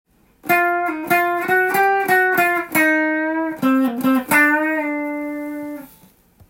Cのブルーノートを使いながらメジャーペンタトニックスケールを使い
最後は９ｔｈのテンション落ち着くという高度なフレーズです。